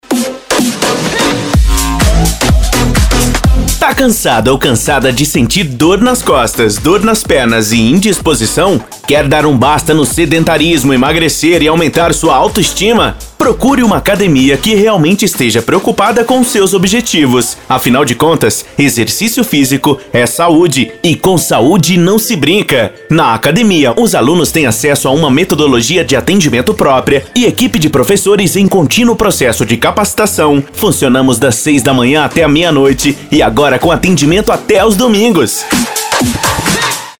Anúncios Personalizados com Locutores Profissionais
spot-academia.mp3